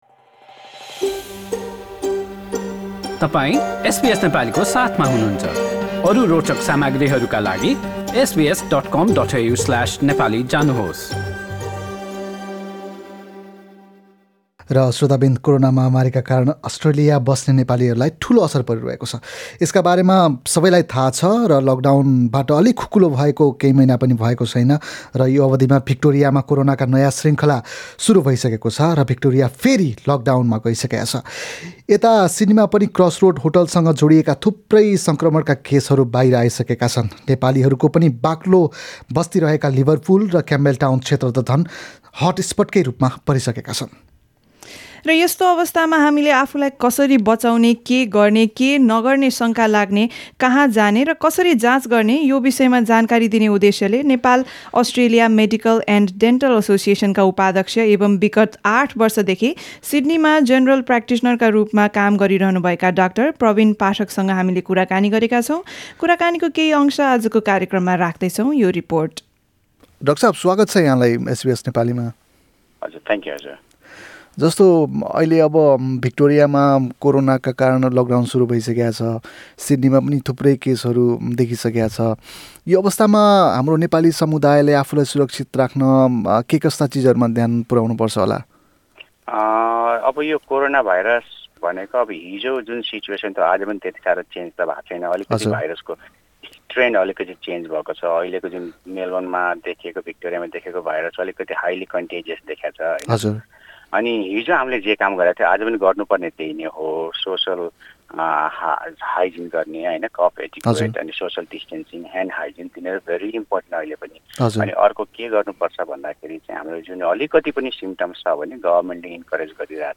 हामीले गरेको कुराकानी।